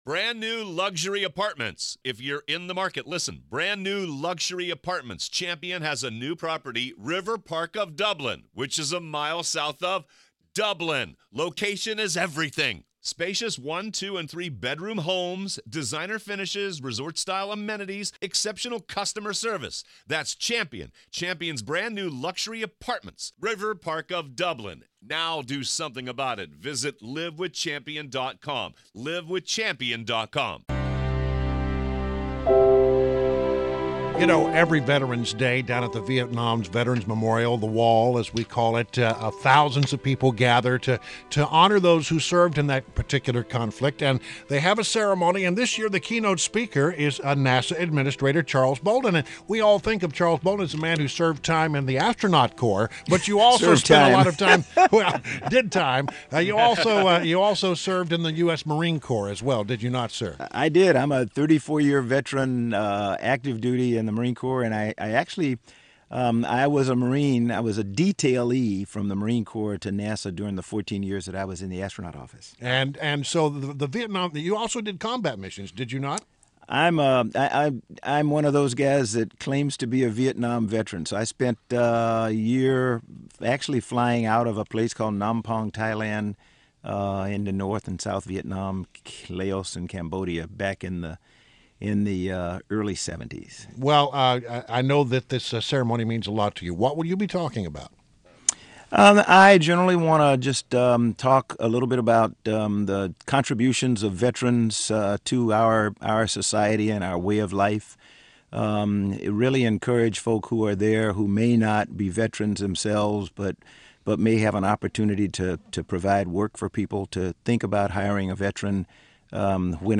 WMAL Interview - NASA's CHARLES BOLDEN - 11.10.16
INTERVIEW – NASA ADMINISTRATOR CHARLES BOLDEN – discussed Veterans Day.